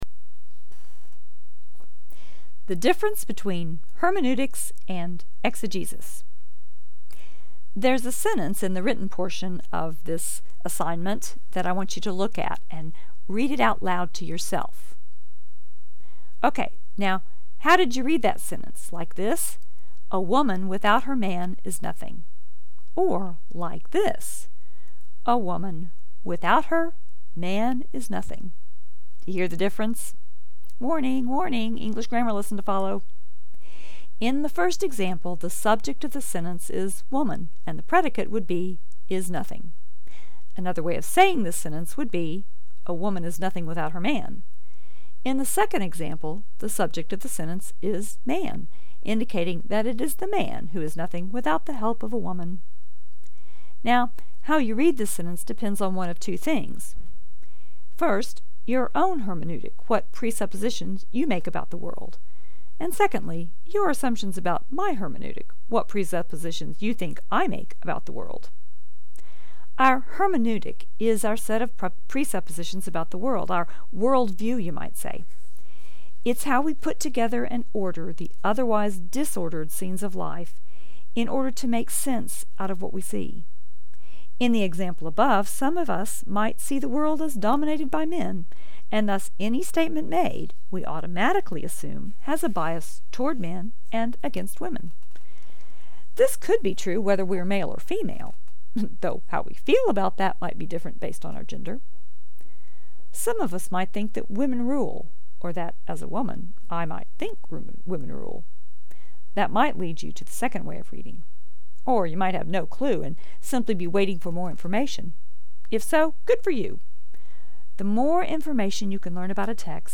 Audio of this post (remember this is from the class!)